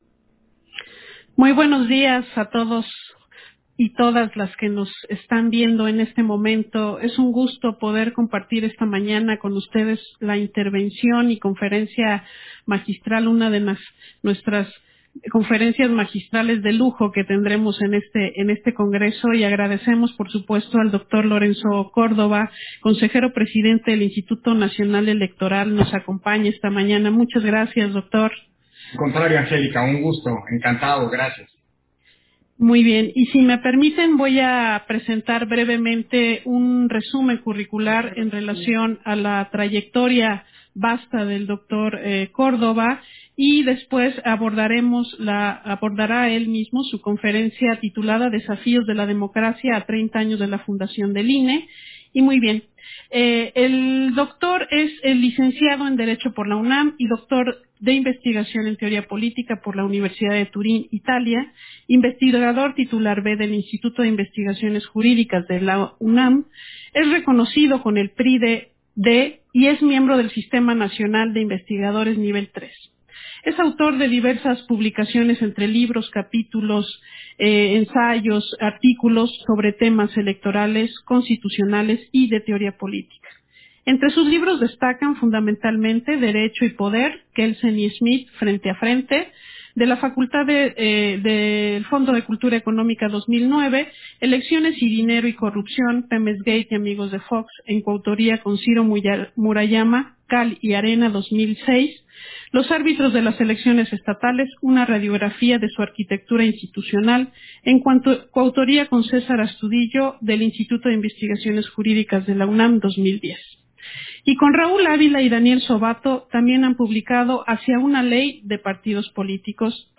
071020_AUDIO_INTERVENCIÓN-CONSEJERO-PDTE.-CÓRDOVA-CONFERENCIA-MAGISTRAL - Central Electoral
Versión estenográfica de la Conferencia Magistral que ofreció Lorenzo Córdova, Desafíos de la democracia mexicana a 30 años de la fundación del INE